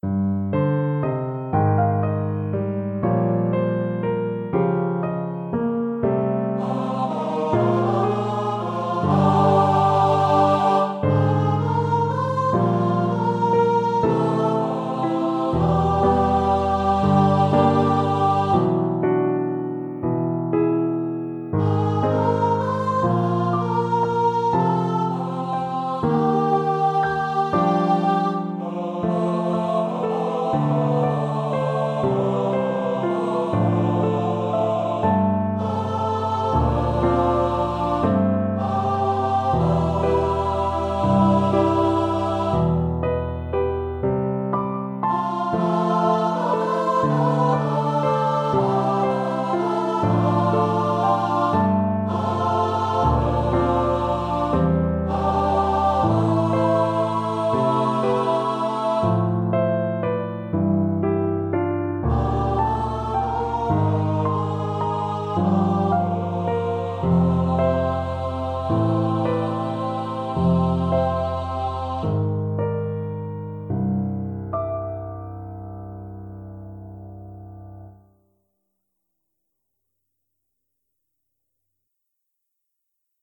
for SATB and Piano